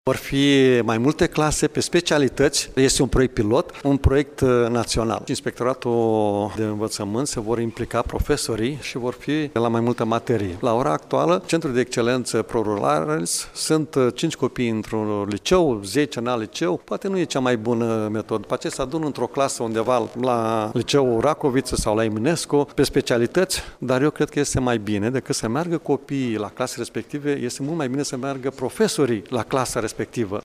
Anunţul a fost făcut, astăzi, de preşedintele Consiliului Judeţean Iaşi, Maricel Popa, care a precizat că proiectul ar putea fi executat atât din bani proprii cât şi cu fonduri de la Guvern.